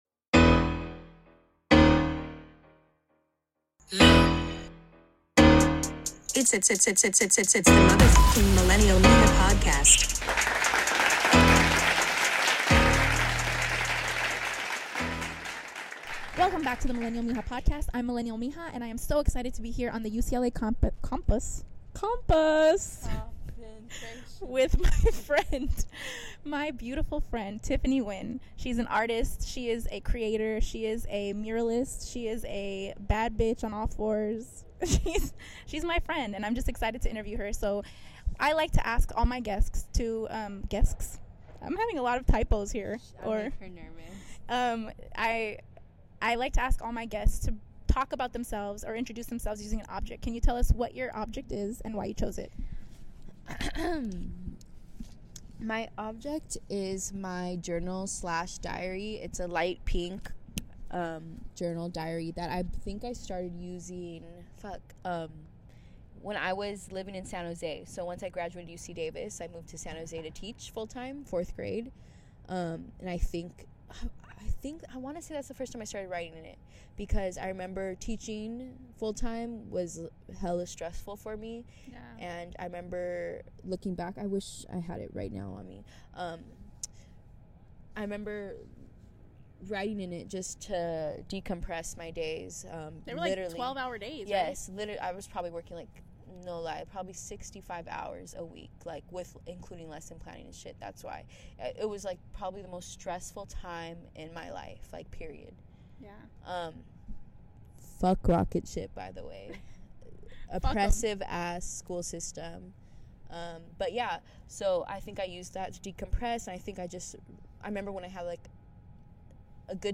Live from UCLA